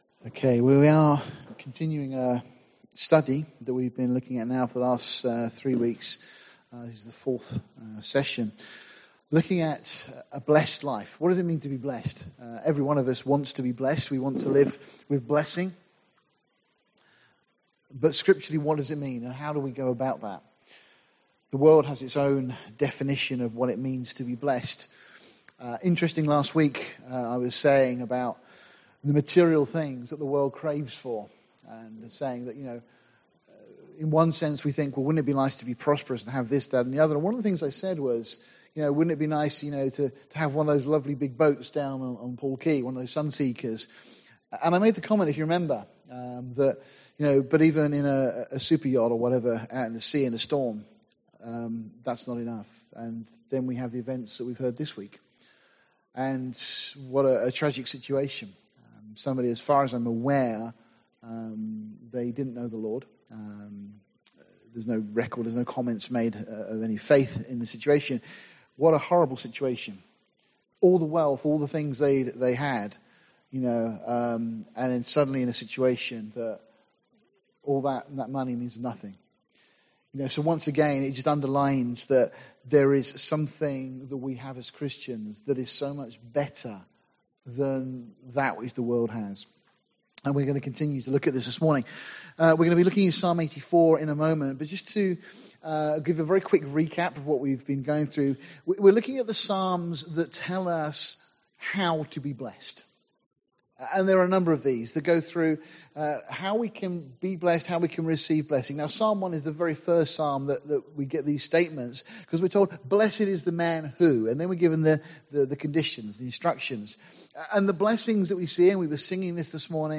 Series: A Blesssed Life , Sunday morning studies , Topical Studies Tagged with topical studies